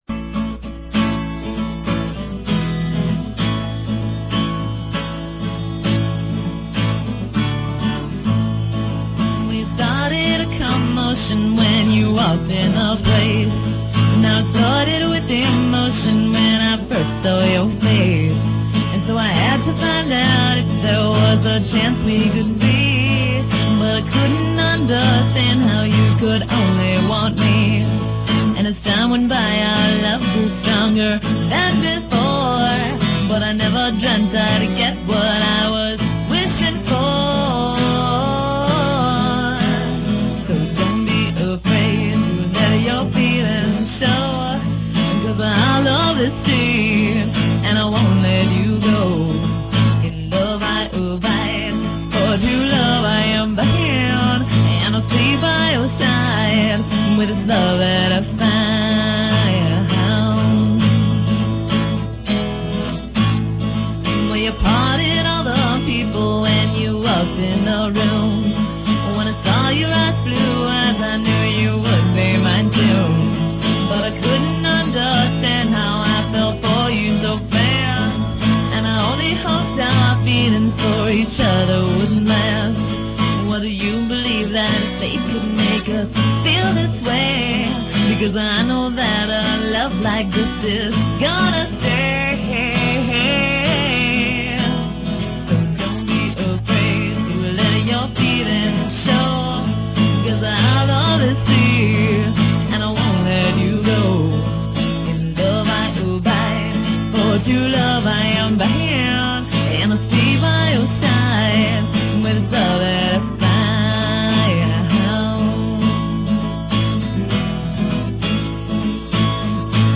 The song is an original and was recorded in Logan Square.